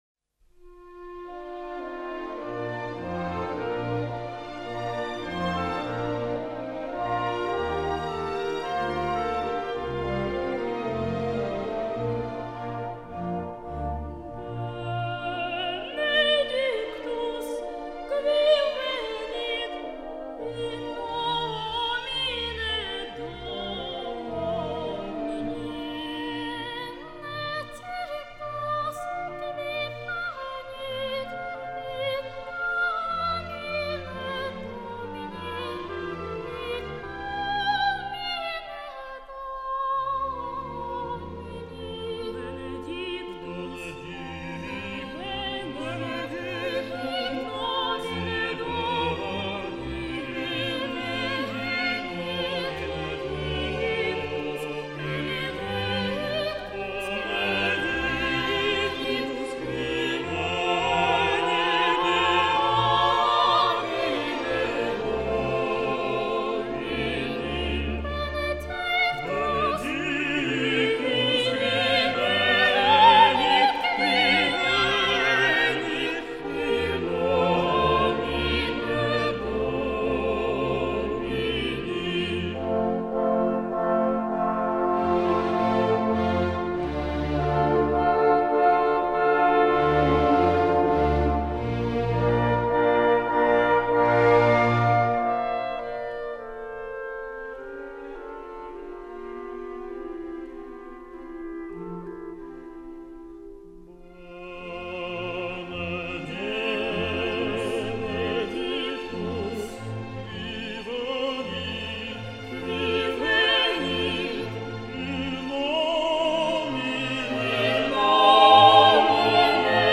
оригинальное исполнение хором мальчиков